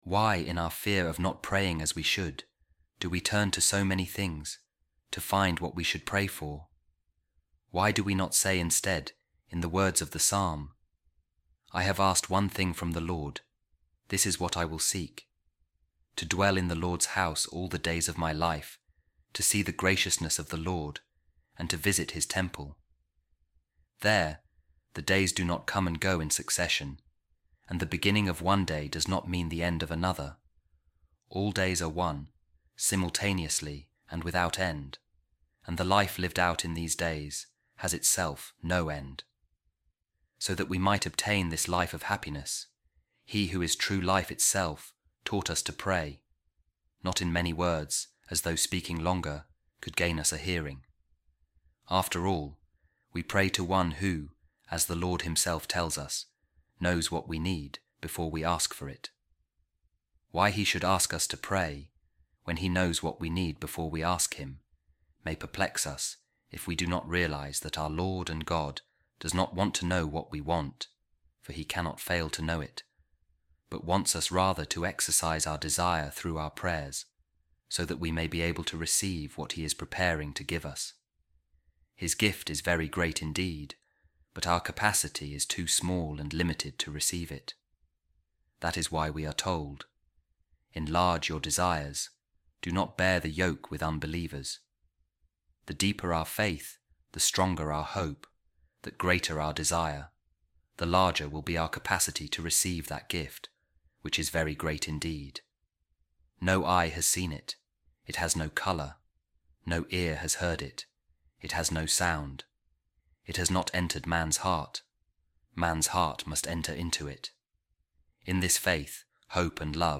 A Reading From The Letter Of Saint Augustine To Proba | Let Our Desire Be Exercised In Prayer